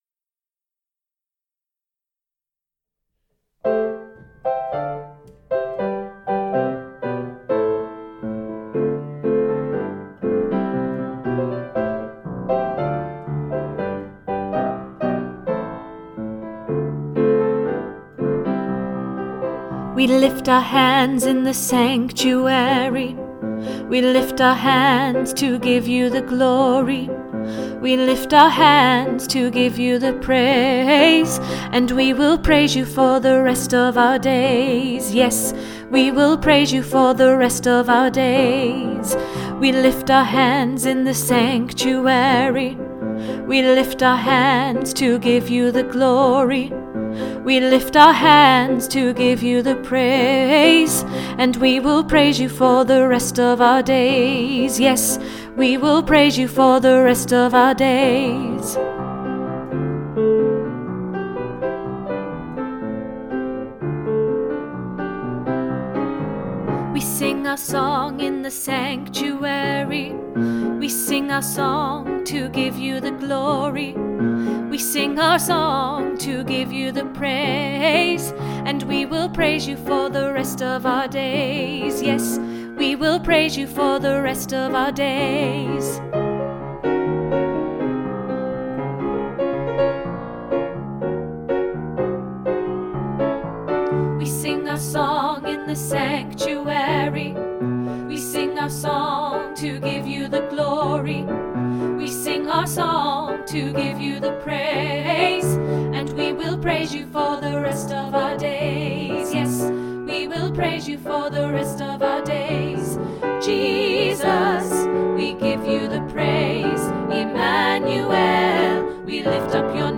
In the Sanctuary SATB